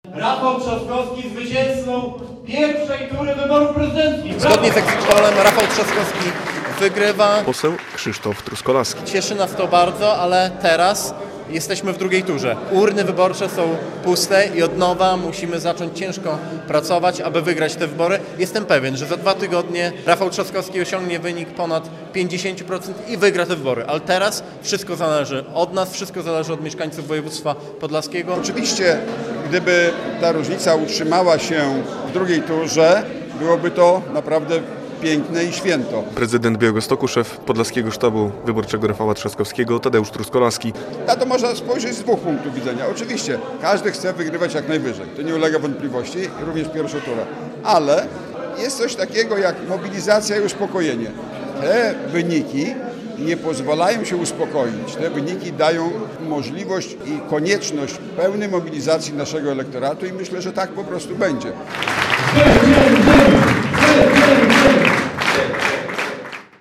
Opinie polityków Koalicji Obywatelskiej o sondażowych wyników I tury wyborów prezydenckich - relacja